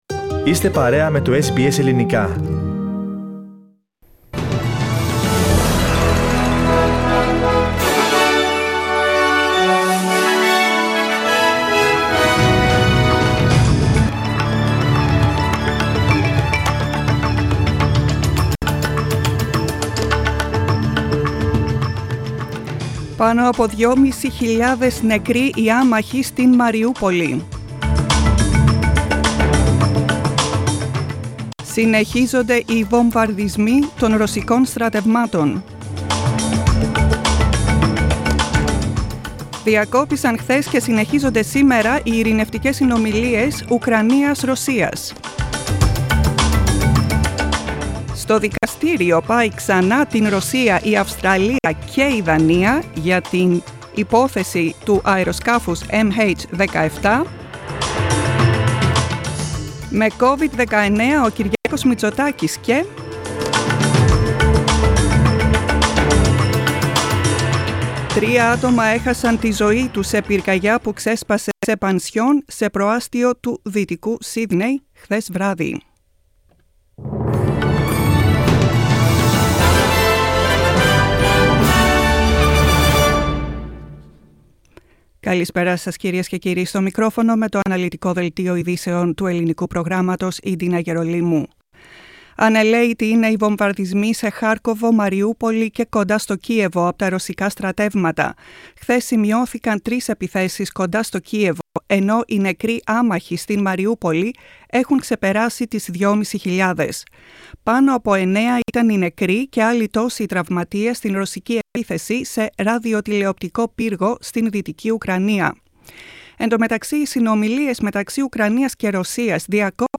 Listen to the main bulletin of the day from the Greek Program of SBS.